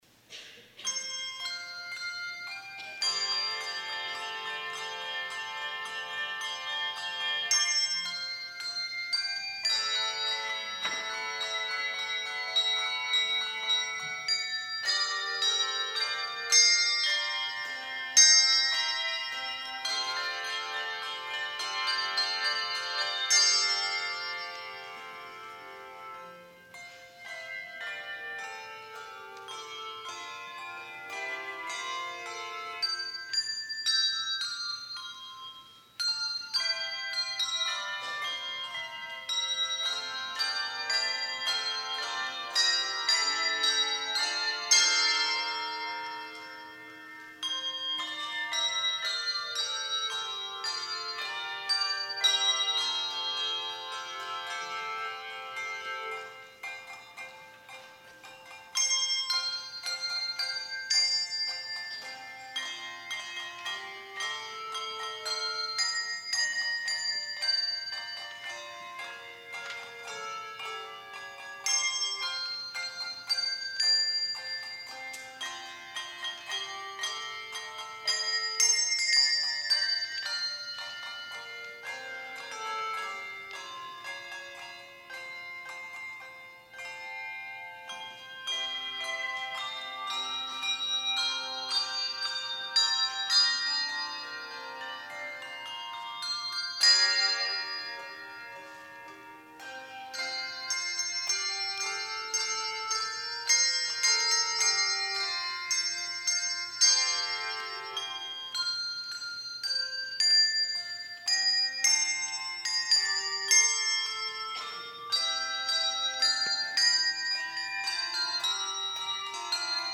Performer:  Handbell Choir